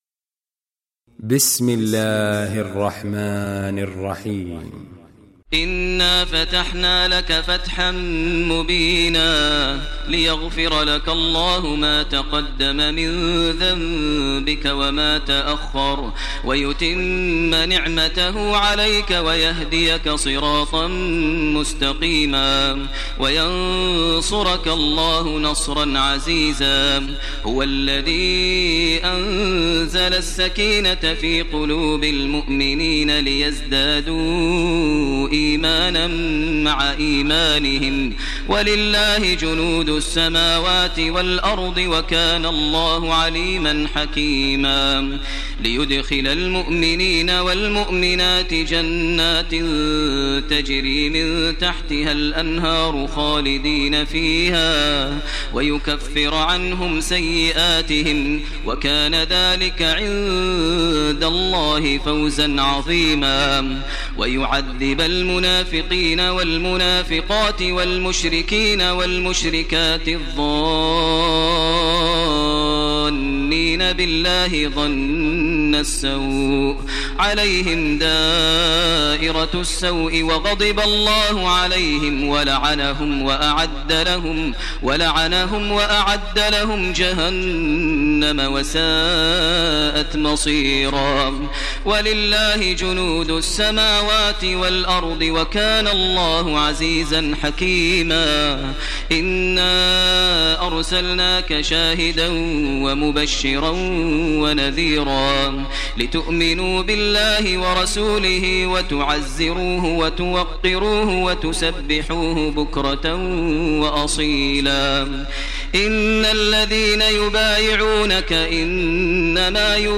Surah Fath Recitation by Sheikh Maher Al Mueaqly
Surah Fath, listen online mp3 tilawat / recitation in Arabic recited by Sheikh Maher al Mueaqly.